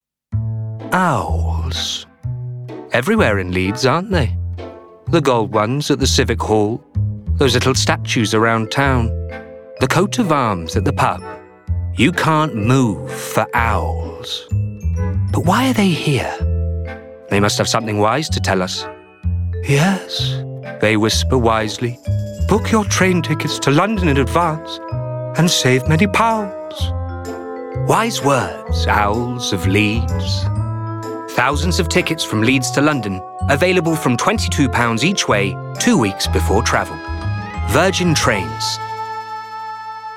• Male
Showing: Commerical Clips
Friendly, Informative, Trusting